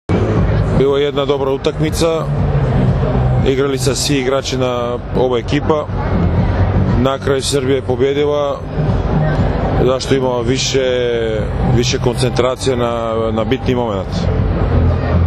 IZJAVA VLADIMIRA NIKOLOVA